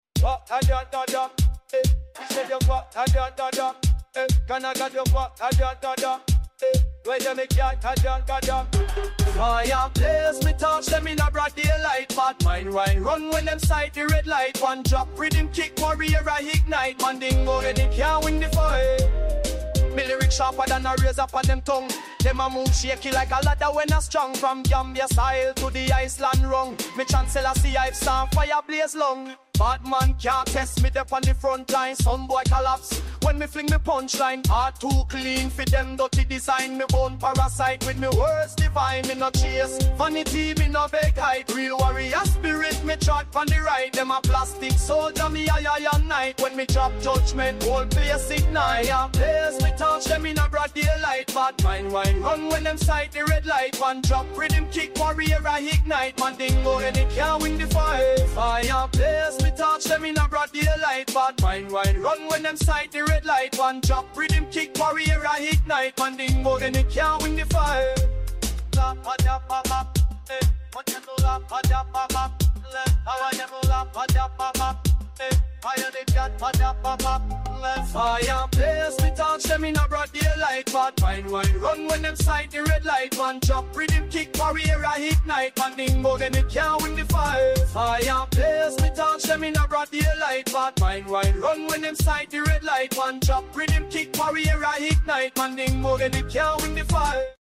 African energy, Helsinki sound system style